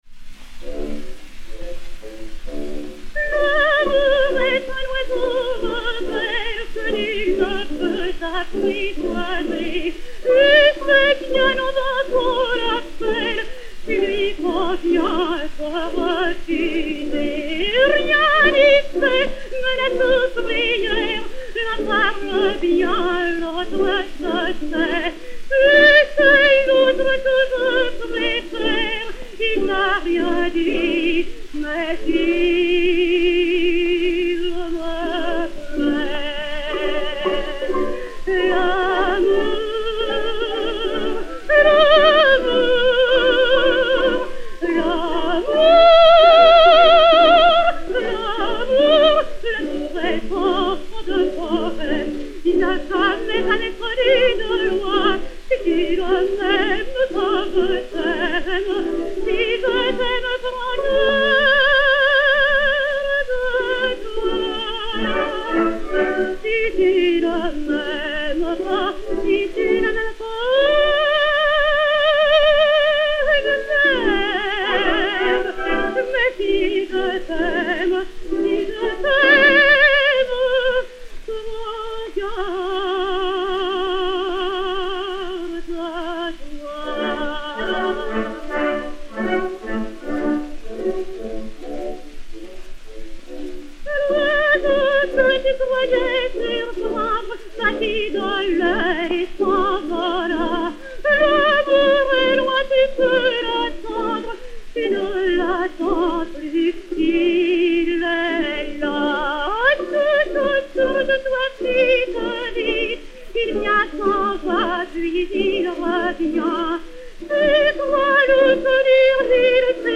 Marthe Chenal (Carmen) et Orchestre dir François Rühlmann